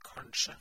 Ääntäminen
RP : IPA : /pəˈhæps/ GenAm: IPA : /pɚˈhæps/